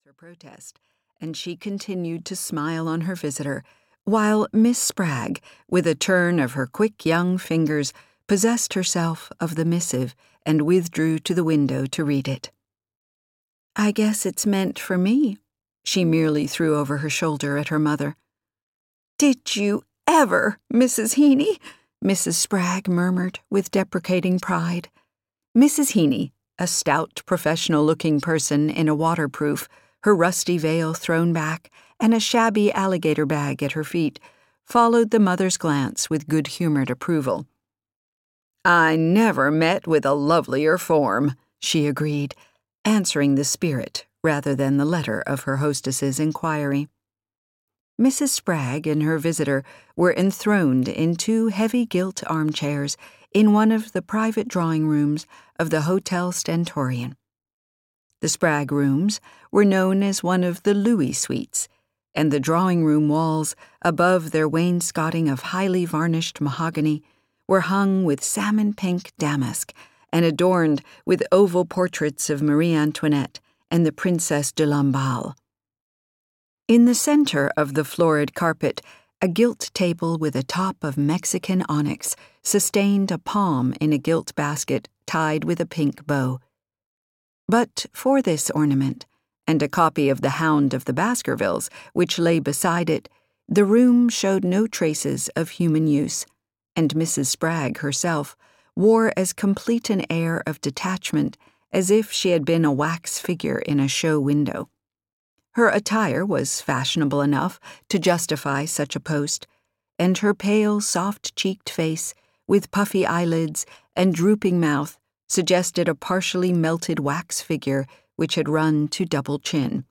The Custom of the Country (EN) audiokniha
Ukázka z knihy